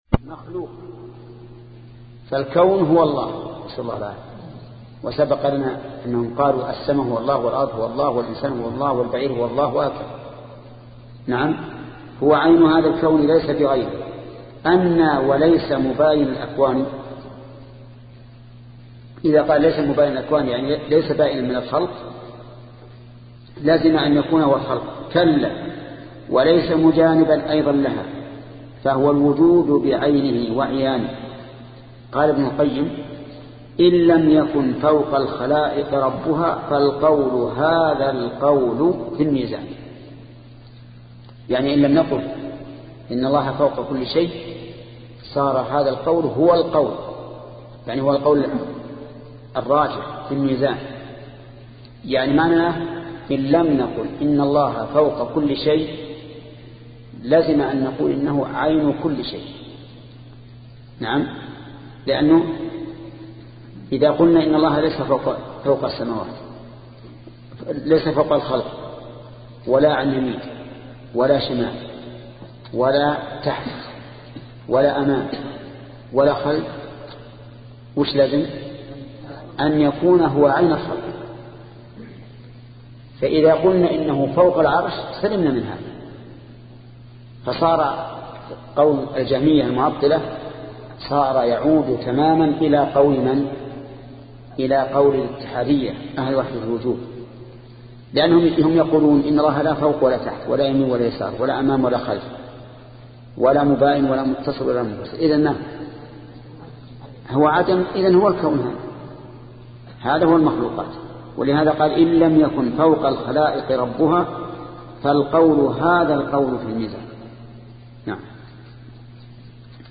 شبكة المعرفة الإسلامية | الدروس | التعليق على القصيدة النونية 13 |محمد بن صالح العثيمين